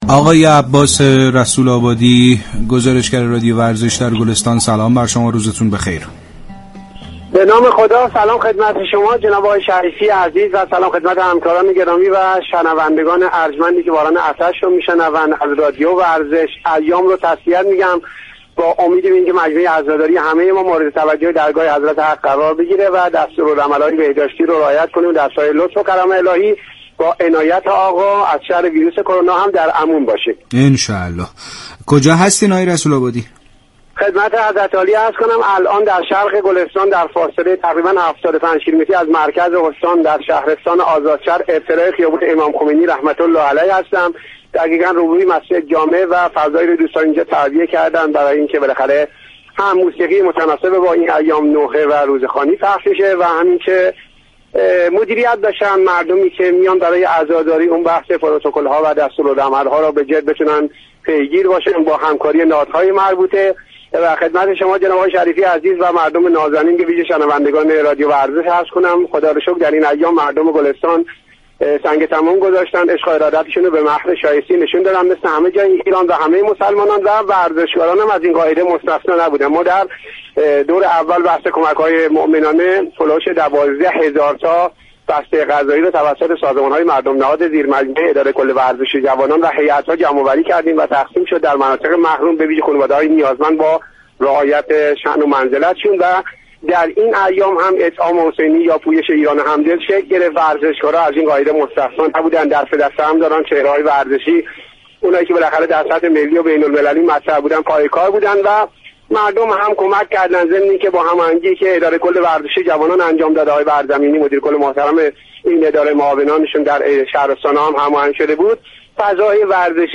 در ایام محرم هم پویش ایران همدل شكل گرفت و چهره های ورزشی نیز دست به دست هم دادند تا كمك ها صورت گیرد. شما می توانید از طریق فایل صوتی پیوست شنونده این گفتگو با رادیو ورزش باشید.